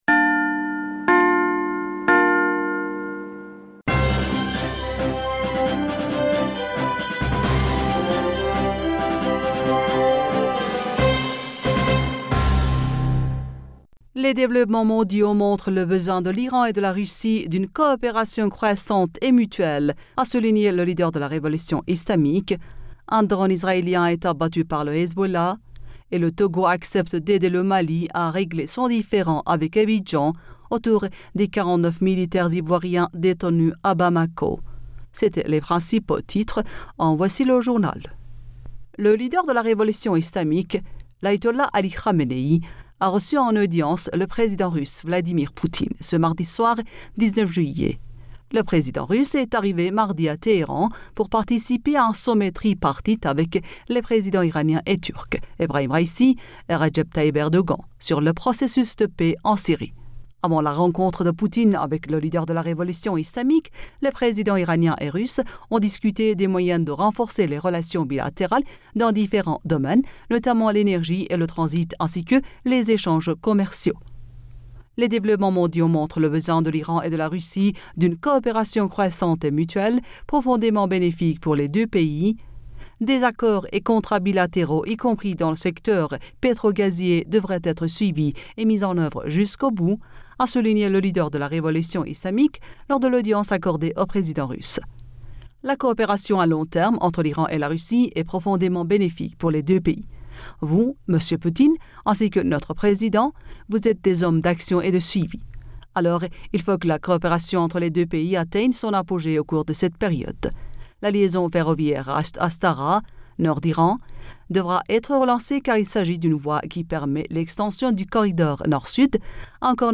Bulletin d'information Du 20 Julliet